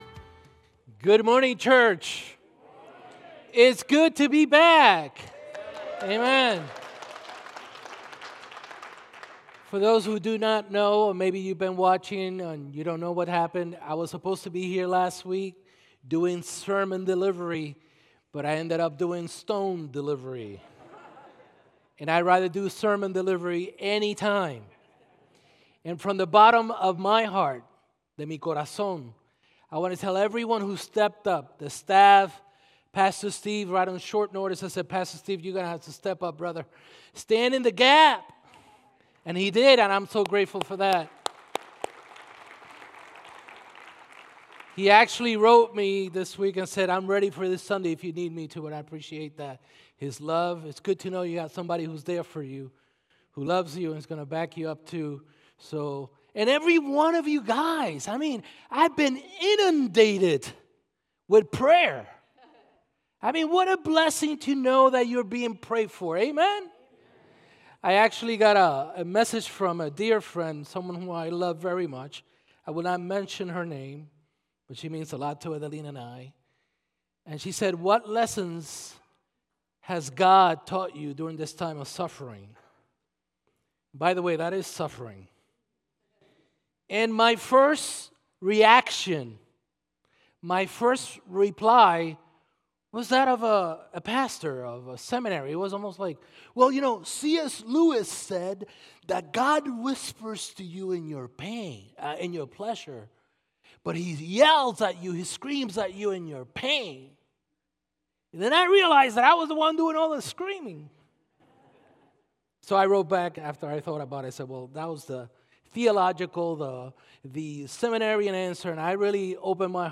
Sermons - Mayfair Bible Church